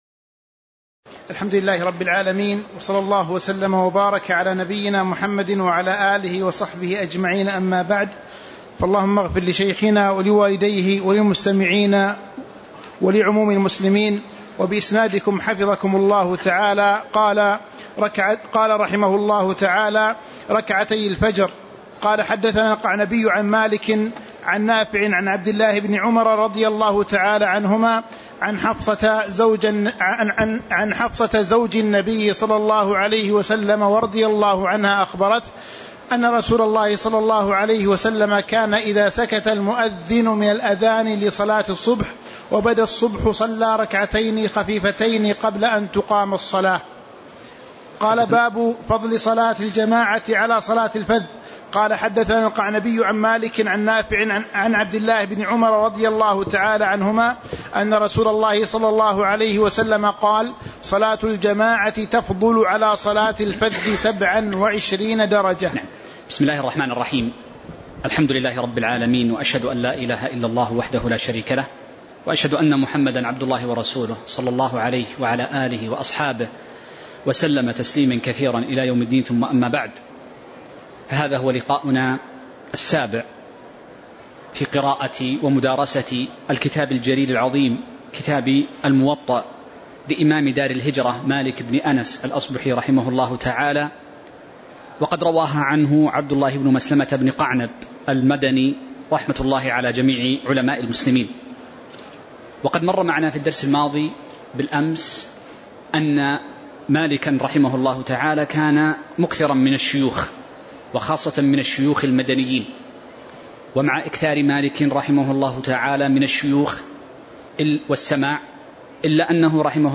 تاريخ النشر ١٨ شعبان ١٤٤٥ هـ المكان: المسجد النبوي الشيخ